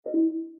buttonClick.mp3